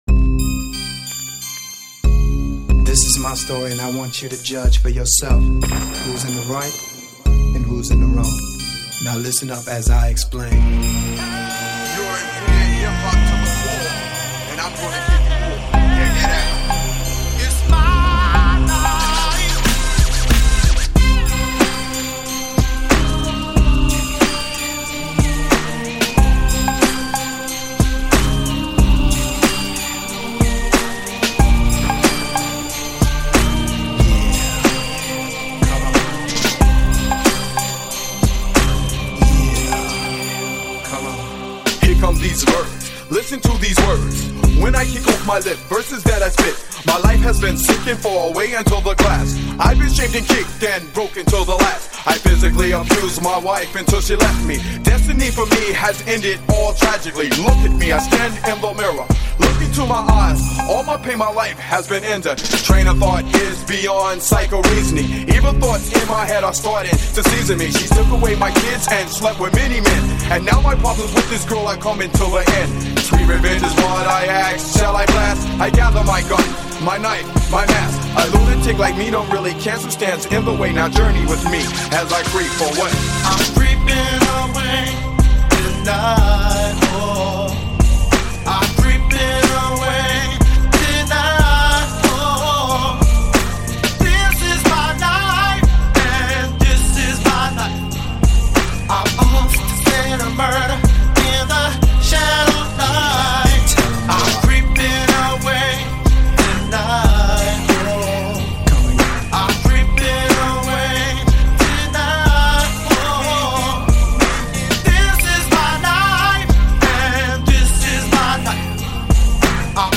Жанр: Hip-Hop
Рэп Хип-хоп